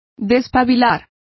Complete with pronunciation of the translation of snuffing.